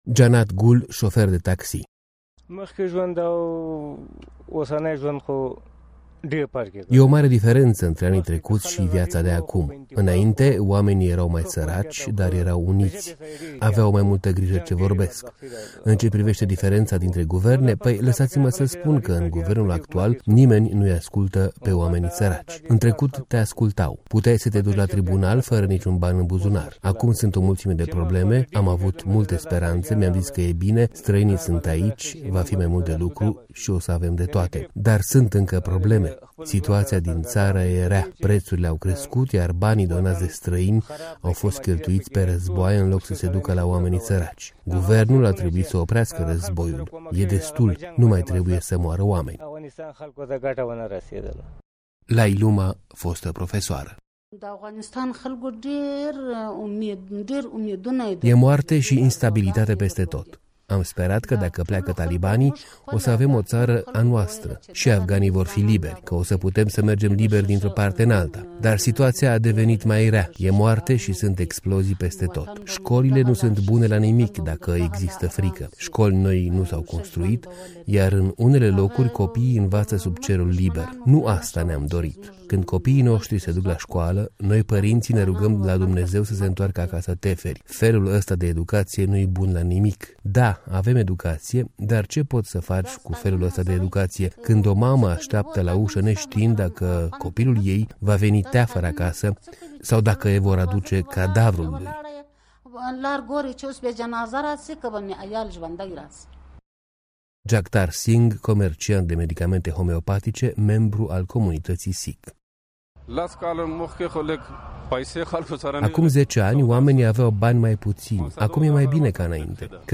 Un sondaj de opinie